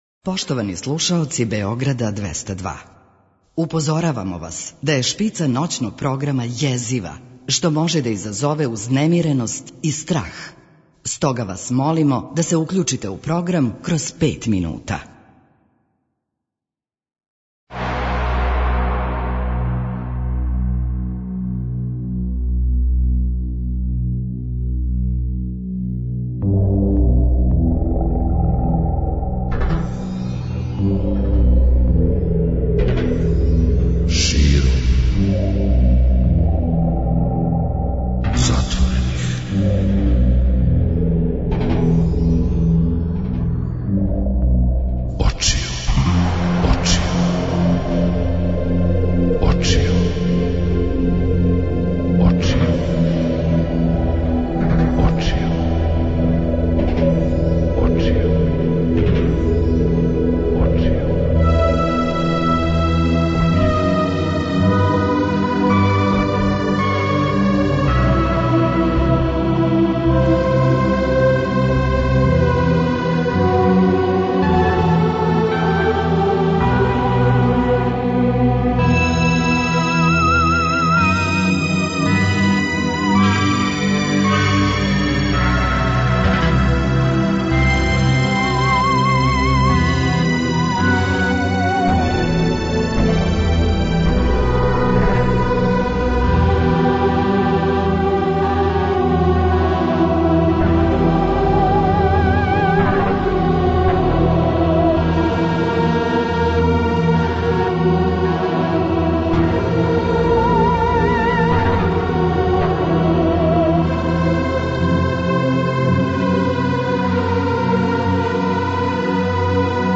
Гост Ноћног програма Широм затворених очију је глумац Иван Бекјарев.